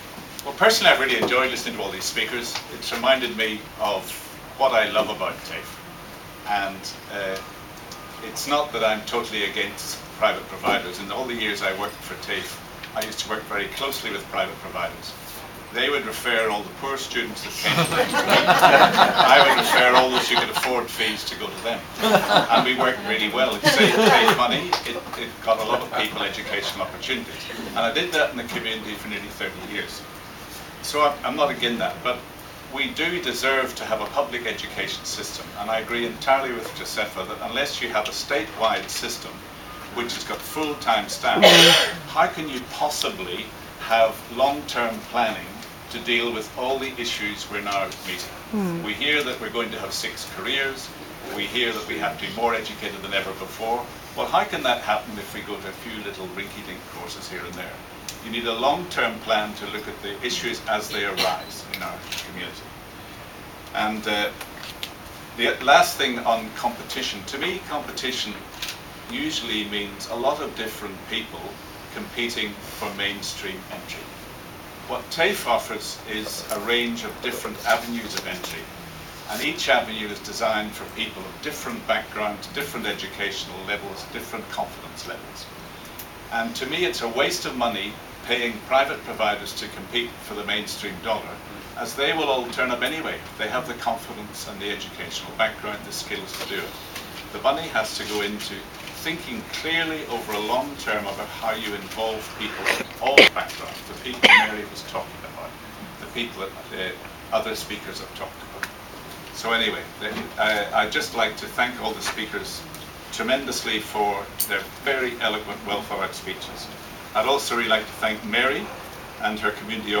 On February 22 2013 the TAFE Community Alliance was formally launched in the heart of western Sydney at Western Sydney Community Forum, Parramatta.